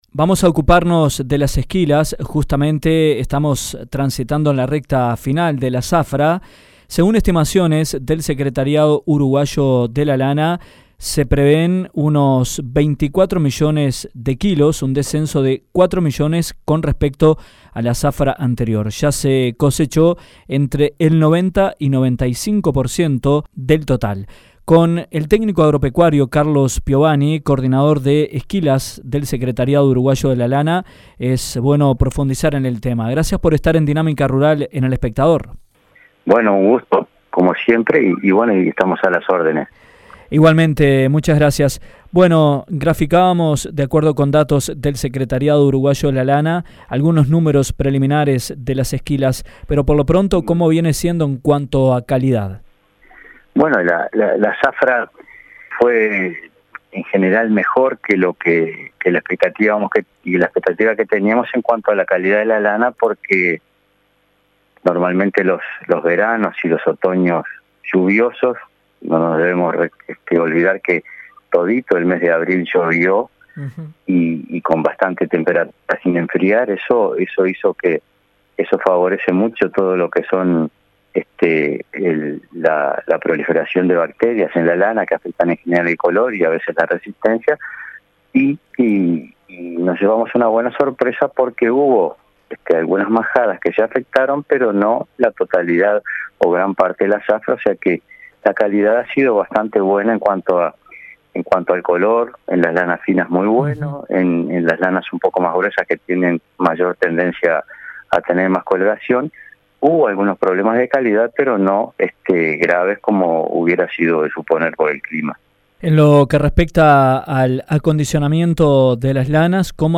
Cuando se transita la recta final de la zafra de esquilas y solo resta cosechar el 5% de la lana, el SUL prevé que la producción total se ubique en 24 millones de kilos, marcando un descenso de 4 millones respecto al año anterior. En entrevista